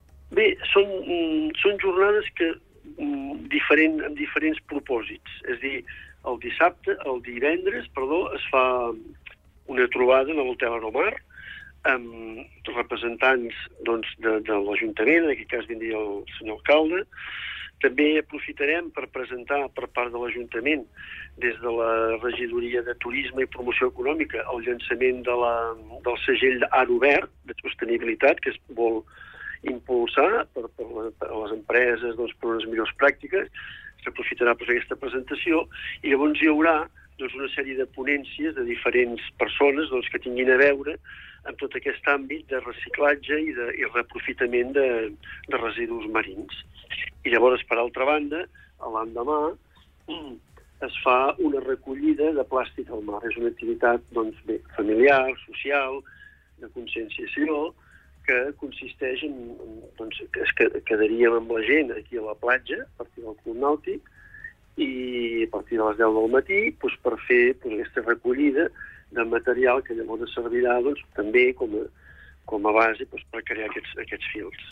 Supermatí - entrevistes